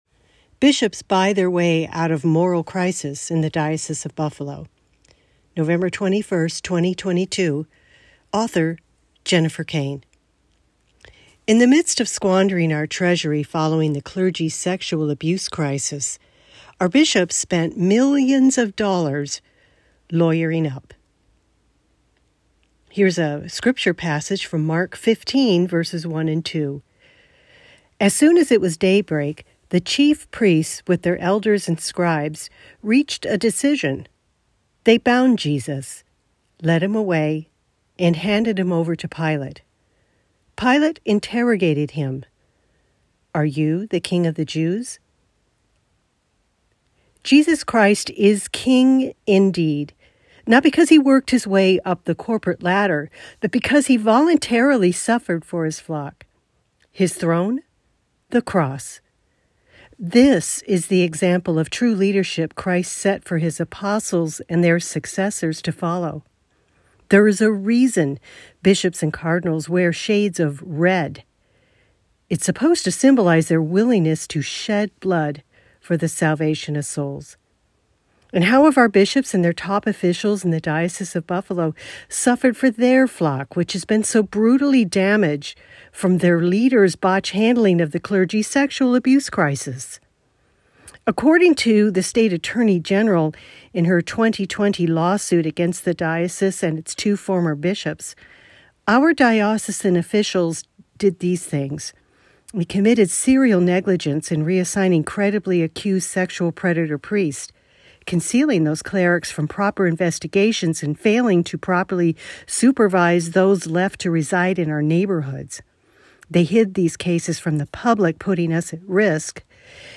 [Author reads this post]